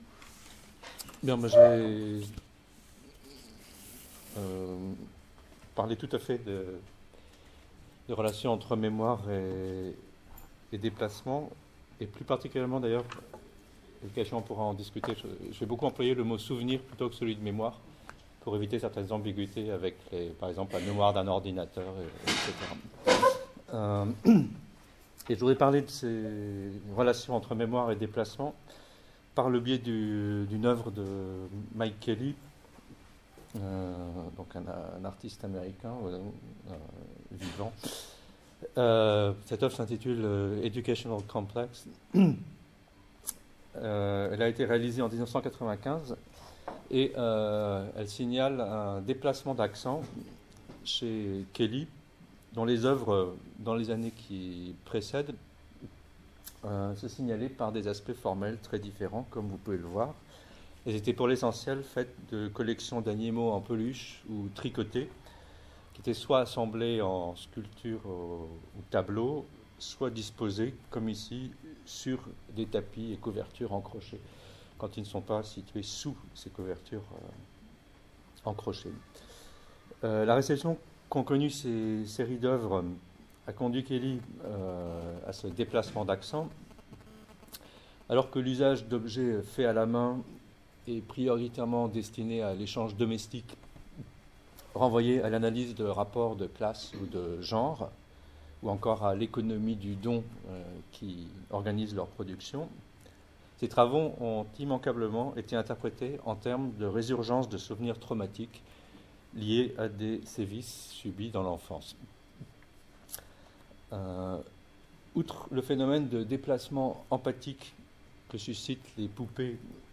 Journée d’étude « Déplacement et mémoire », Mardi 19 mai 2009, de 10h à 17h, Université Paris 8/UFR Arts, salle A1-172